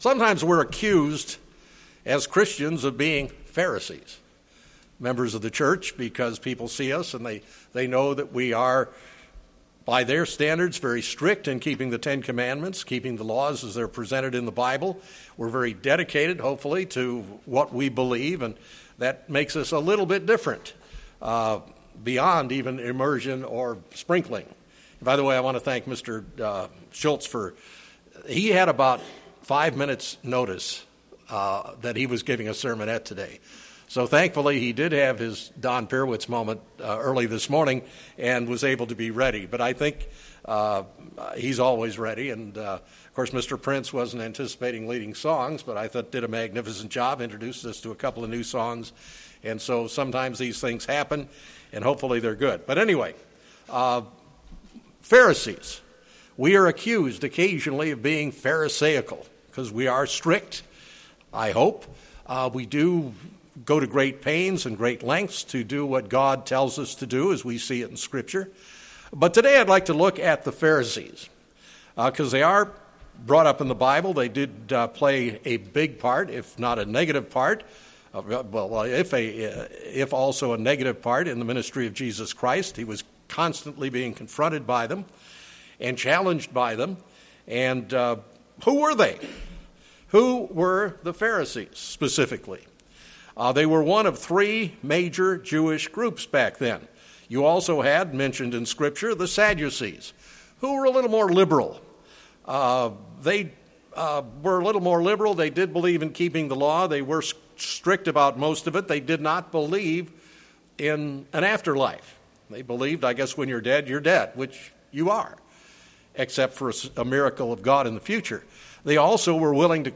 Given in Springfield, MO
UCG Sermon Studying the bible?